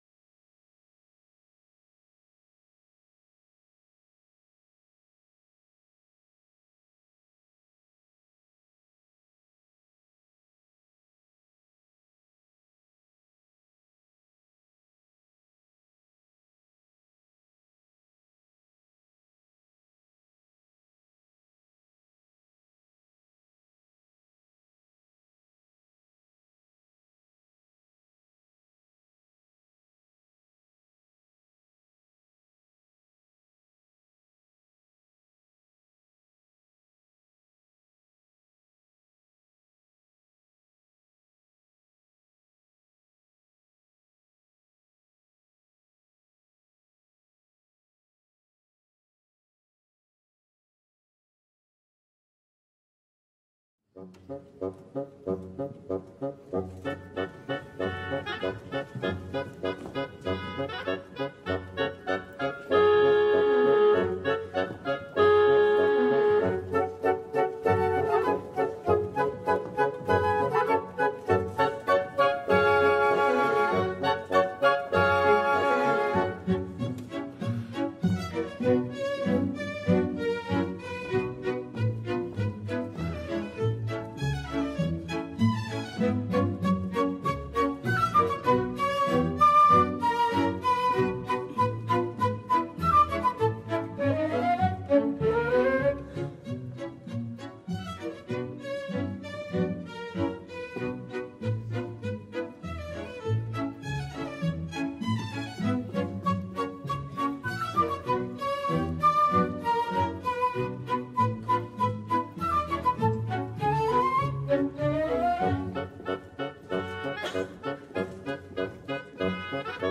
Статус в Париже Екатерина Шульман и Максим Курников